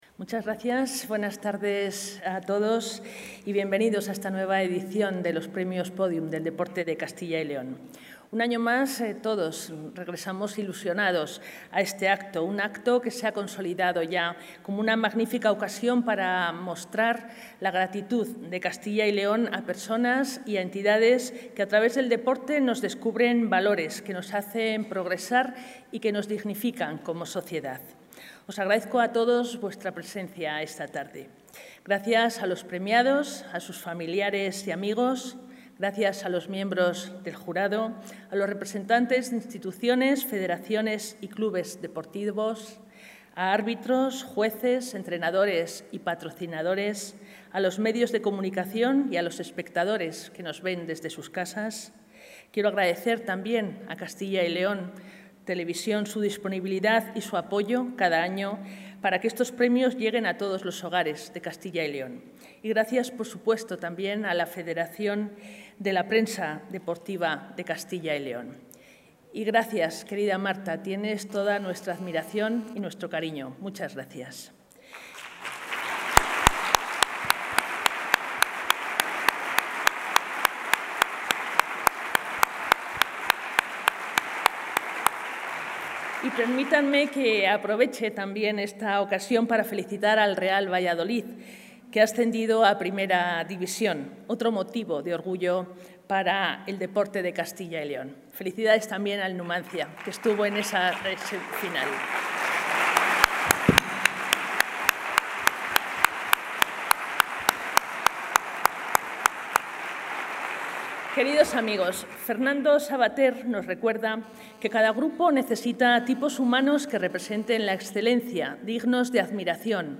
Consejera de Cultura y Turismo.
La Junta de Castilla y León celebra esta tarde la ceremonia de entrega de la sexta edición Premios Pódium del Deporte de Castilla y León, que reconocen los méritos deportivos y el esfuerzo, tanto de deportistas como de entidades que han contribuido al desarrollo y difusión del deporte castellano y leonés.